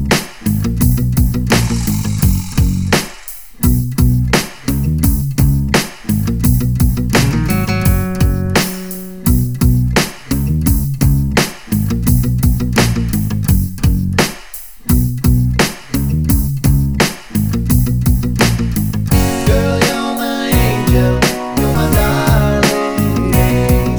no Backing Vocals Reggae 3:54 Buy £1.50